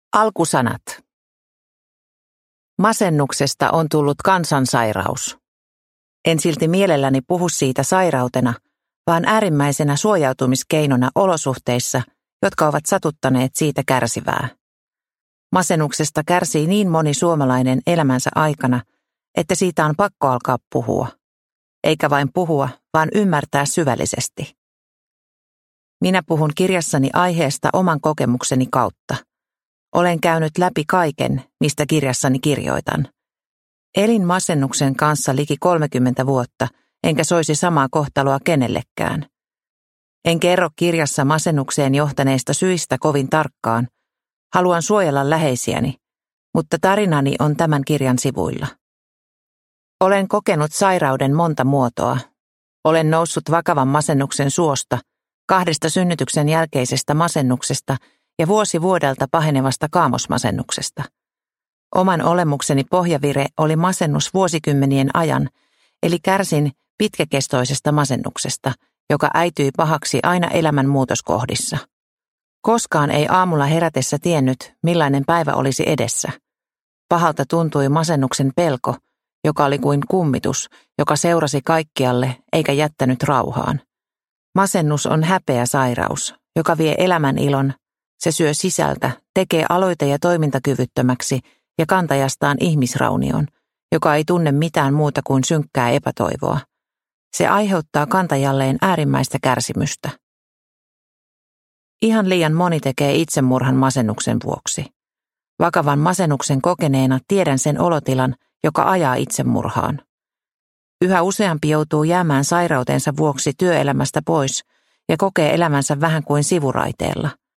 Toivon kirja masennuksesta – Ljudbok – Laddas ner